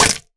Minecraft Version Minecraft Version latest Latest Release | Latest Snapshot latest / assets / minecraft / sounds / mob / goat / horn_break1.ogg Compare With Compare With Latest Release | Latest Snapshot
horn_break1.ogg